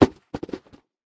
fallingturnips.ogg